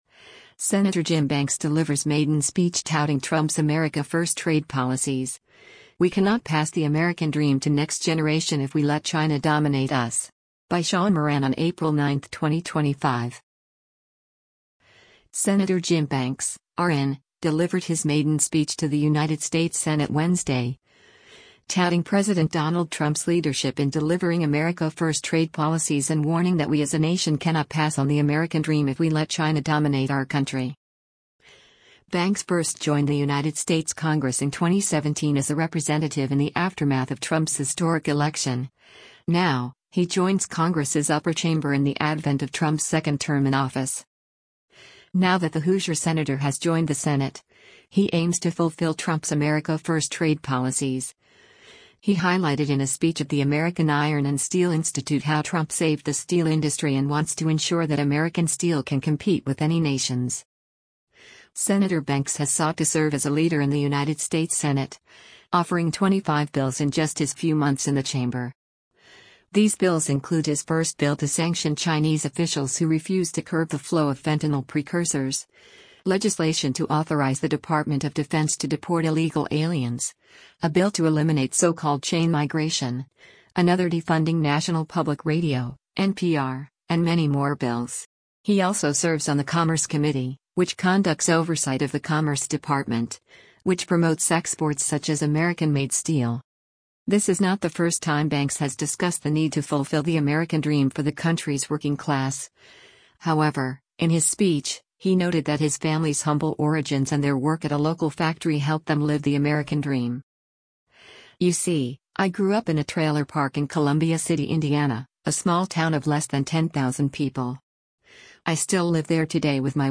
Sen. Jim Banks Delivers Maiden Speech Touting Trump’s America First Trade Policies: We Cannot Pass the American Dream to Next Generation If We Let China ‘Dominate Us’
Sen. Jim Banks (R-IN) delivered his maiden speech to the United States Senate Wednesday, touting President Donald Trump’s leadership in delivering America First trade policies and warning that we as a nation cannot pass on the American Dream if we let China dominate our country.